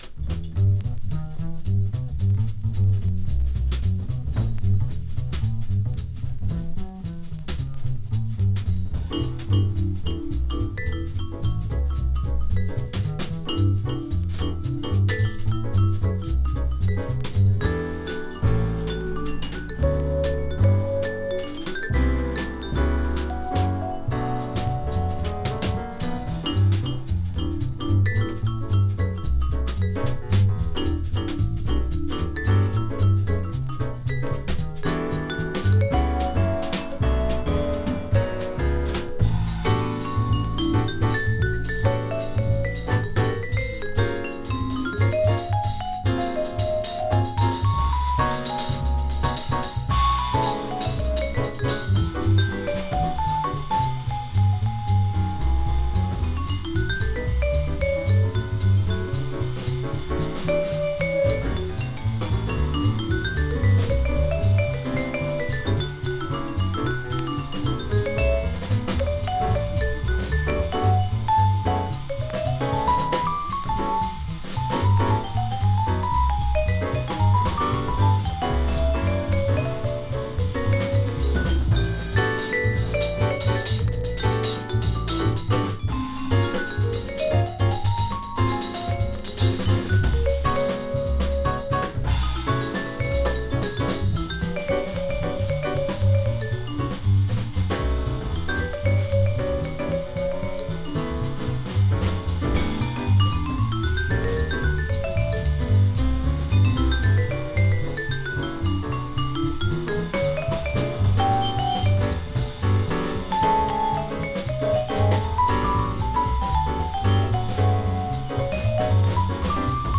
Vibes
Piano
Bass
Drums
sticking strictly to straight-ahead jazz
It sounds like a late-nite radio show theme.